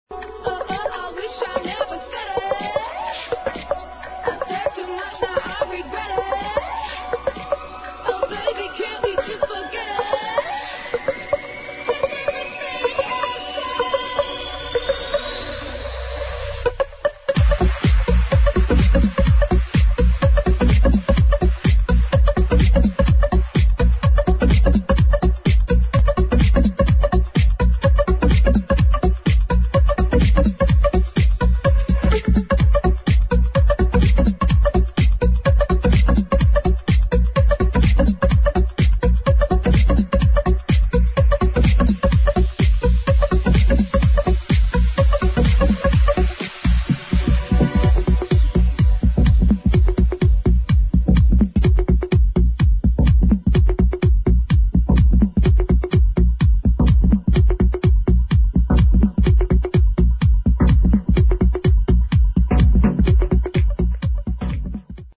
[ HOUSE | ELECTRO ]